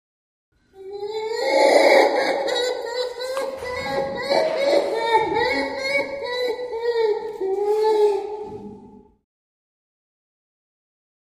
Gorilla Howling, Whimpering. Series Of Low Pitched Reverberated Hoots. Medium Perspective.